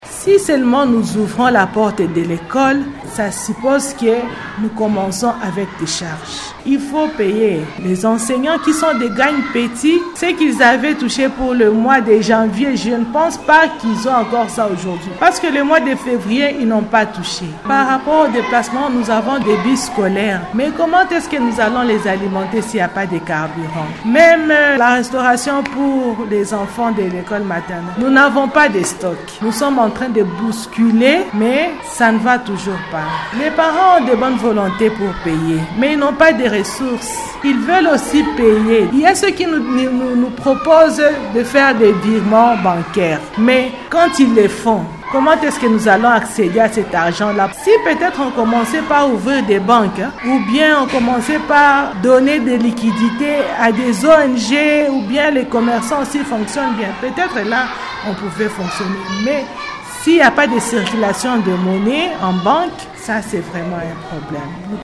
Pour les responsables d’écoles, il serait mieux que les autorités  mettent en place des mécanismes pour faciliter la réouverture des banques ainsi que la reprise des activités, explique sous anonymat une gestionnaire d’école à Bukavu.